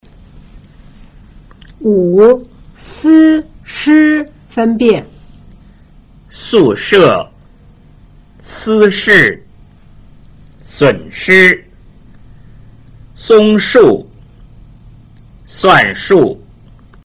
1. 平舌音 z c s和 捲舌音 zh ch sh 的比較﹕
5） s – sh分辨